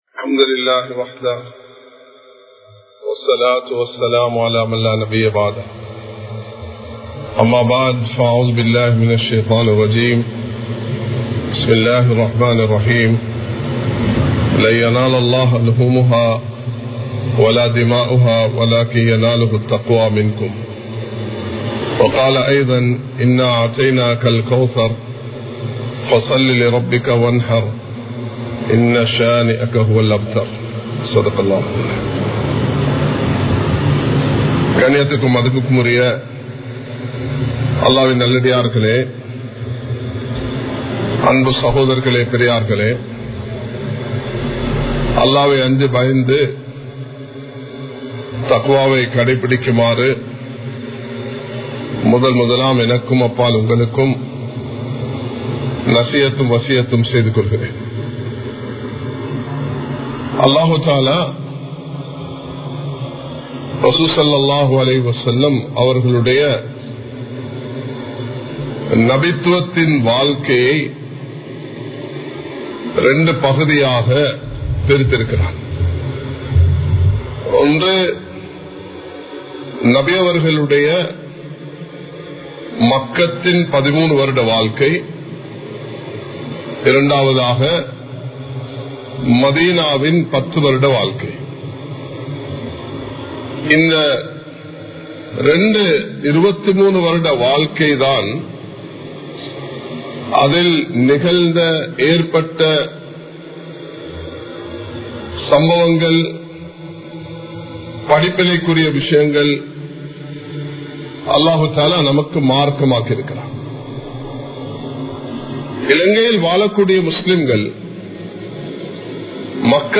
எங்களது உரிமைகளை எவ்வாறு பாதுகாப்பது? (How to Safeguard Our Rights?) | Audio Bayans | All Ceylon Muslim Youth Community | Addalaichenai
Kollupitty Jumua Masjith